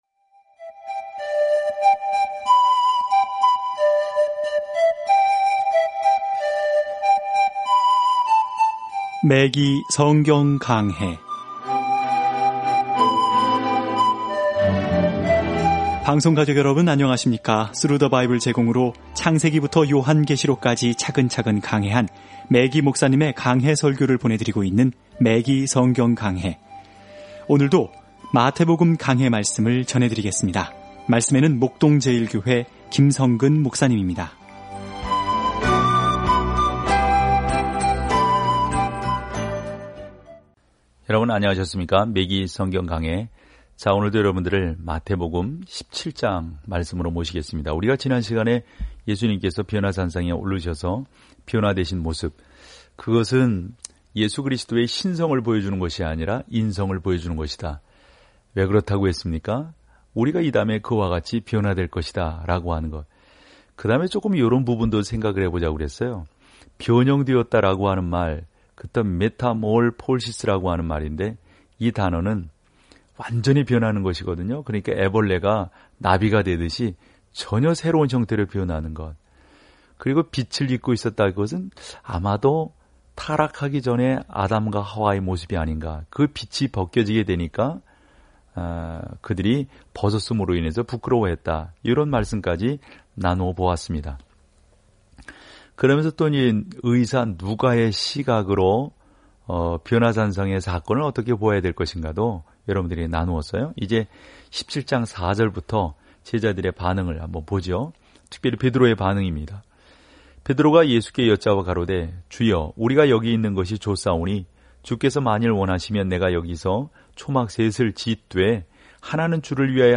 말씀 마태복음 17:4-27 마태복음 18:1-3 39 묵상 계획 시작 41 묵상 소개 마태는 예수님의 삶과 사역이 어떻게 구약의 예언을 성취했는지 보여줌으로써 예수님이 그들의 메시야라는 좋은 소식을 유대인 독자들에게 증명합니다. 오디오 공부를 듣고 하나님의 말씀에서 선택한 구절을 읽으면서 매일 마태복음을 여행하세요.